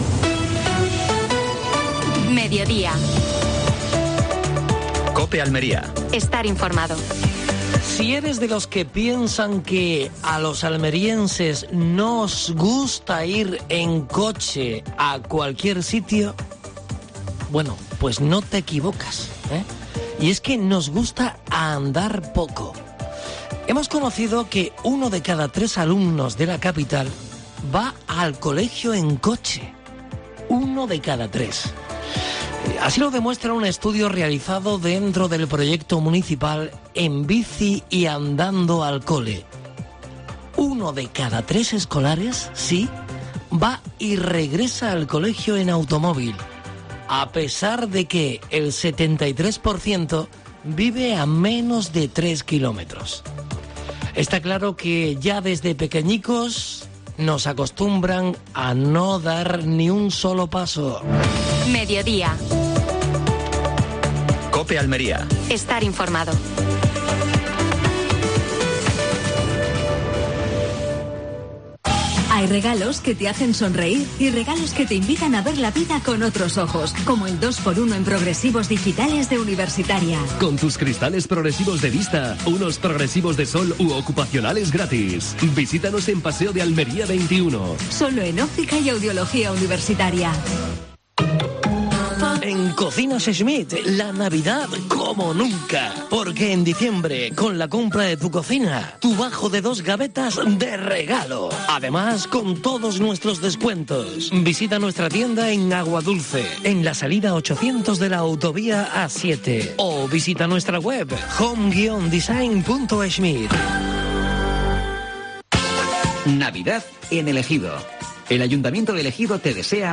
AUDIO: Actualidad en Almería. Entrevista a Manuel Cortés (alcalde de Adra). Última hora deportiva.